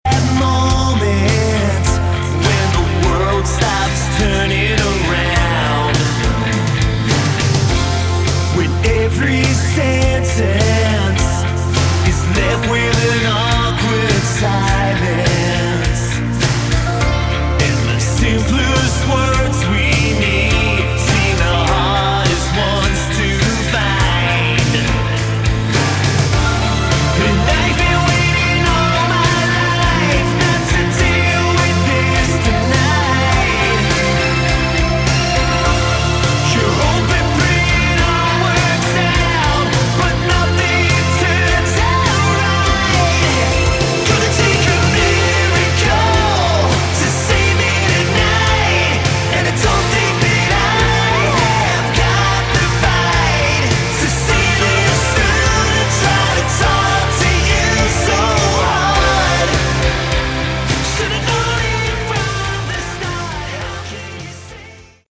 Category: Hard Rock
vocals
guitar
keyboards
bass
drums